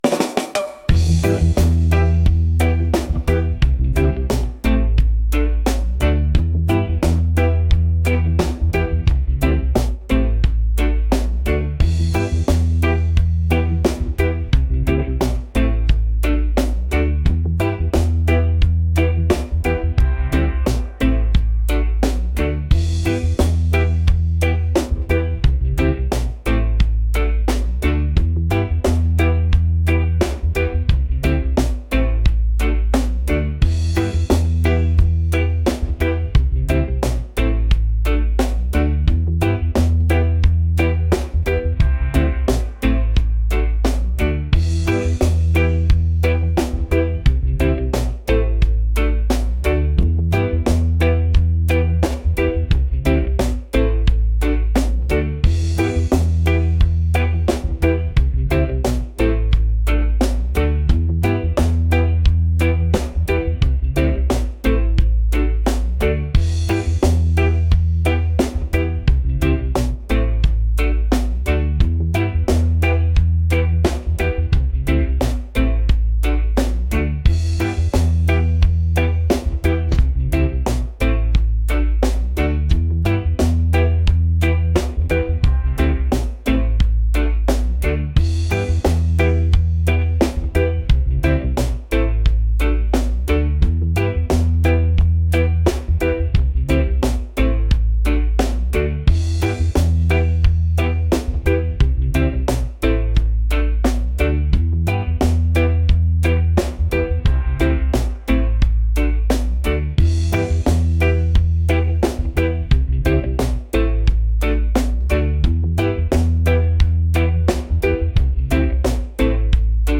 groovy | reggae | relaxed